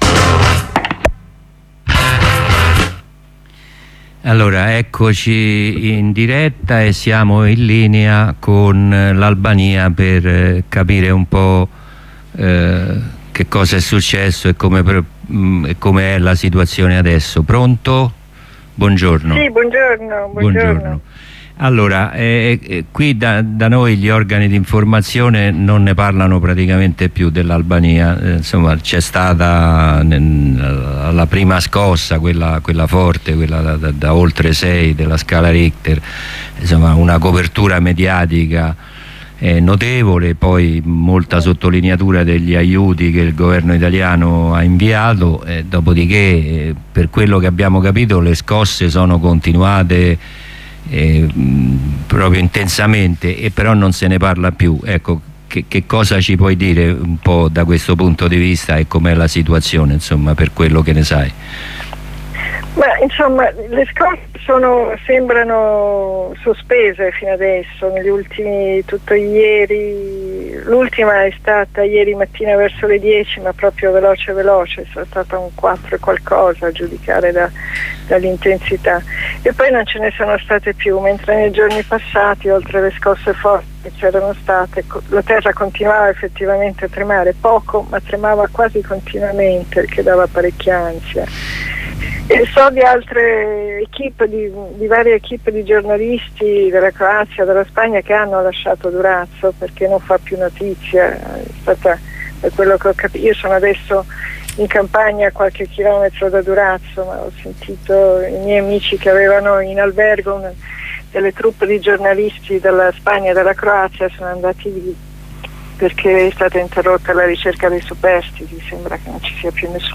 Ne parliamo con la giornalista